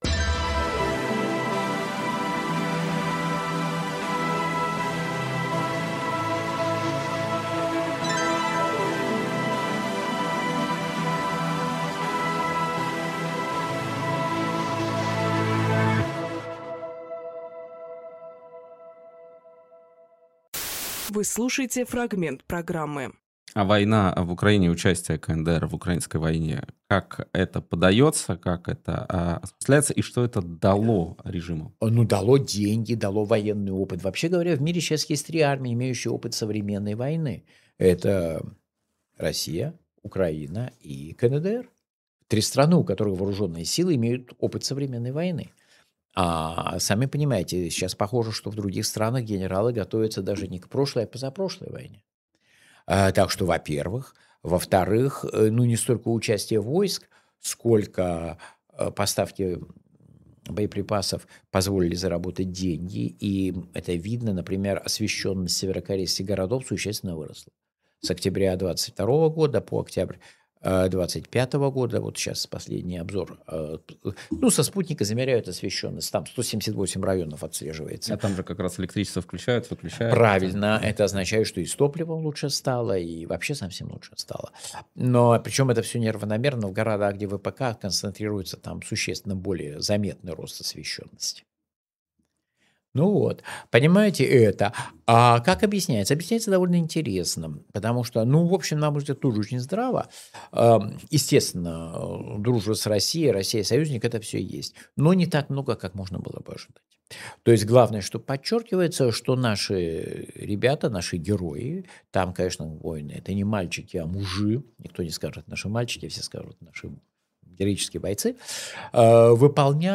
Фрагмент эфира от 24.02.26